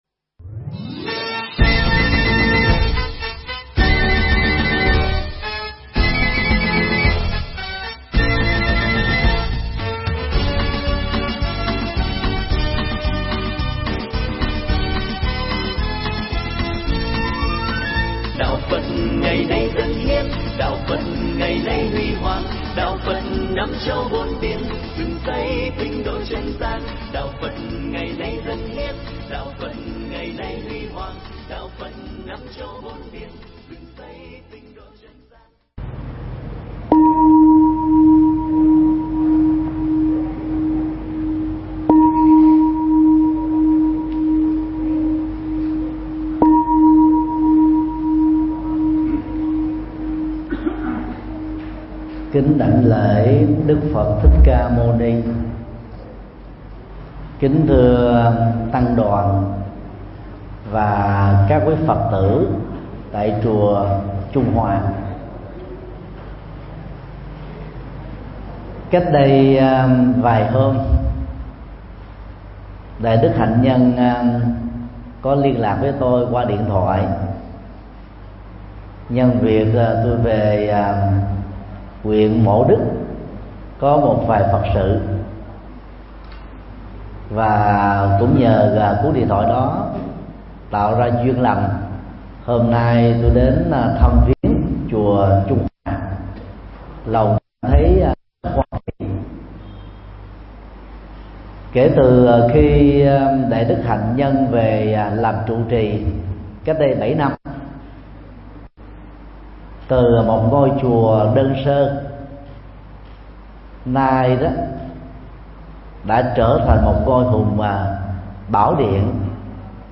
Mp3 Thuyết Giảng Bốn Đức Tính Cao Quý – Thượng Tọa Thích Nhật Từ giảng tại chùa Trung Hòa (Quảng Ngãi), ngày 31 tháng 8 năm 2017